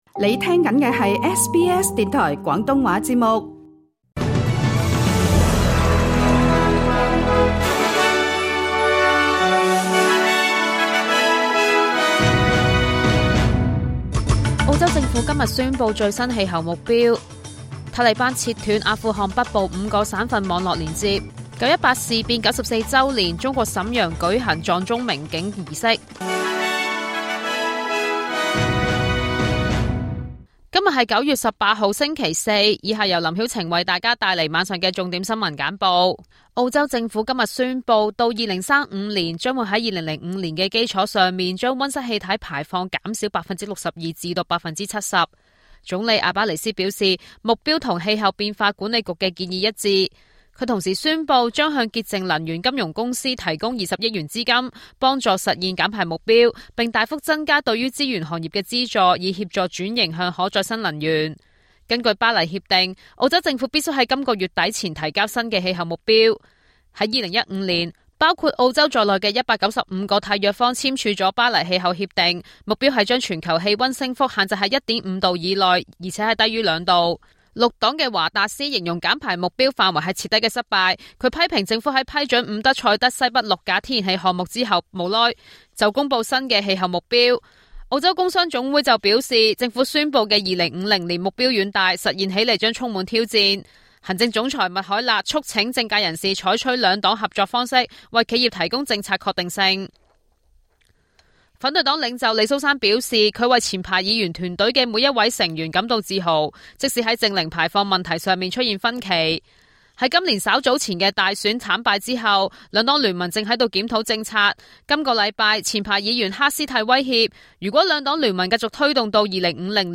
SBS晚間新聞（2025年9月18日）
SBS 廣東話晚間新聞